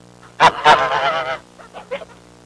Goose Call Sound Effect
Download a high-quality goose call sound effect.
goose-call.mp3